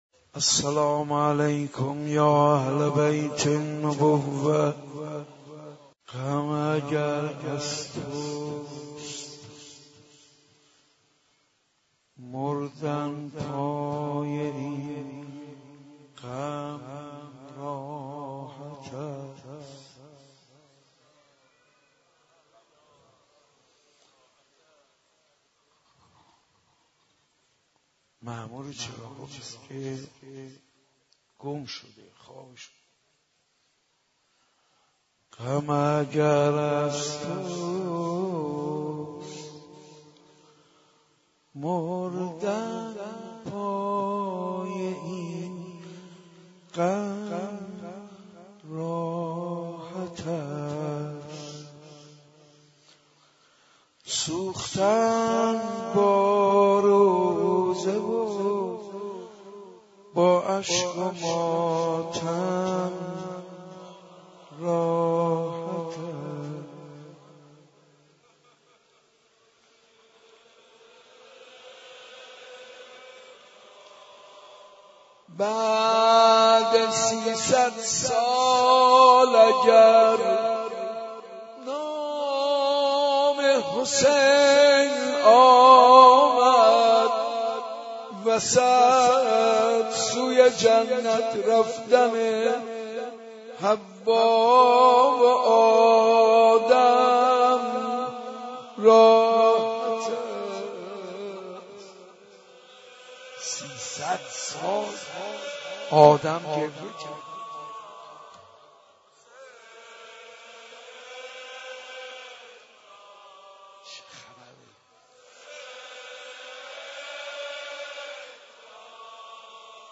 حاج منصور ارضی/شب چهارم محرم95/مسجد ارک